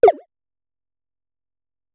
add pickup sounds
select_006.wav